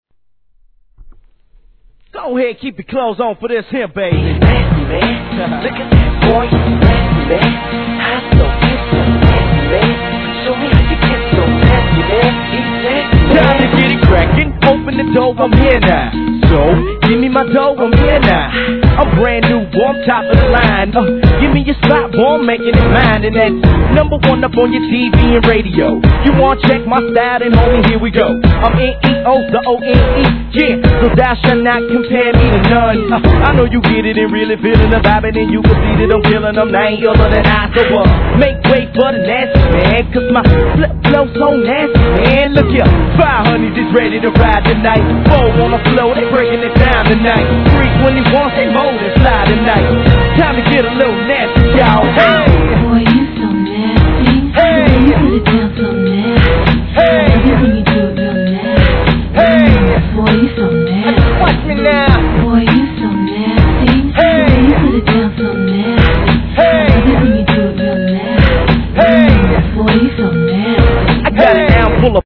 HIP HOP/R&B
ノリのあるビートにウネル上音が中毒性高いトラックがフロア向き!!